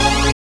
SYN DANCE0BR.wav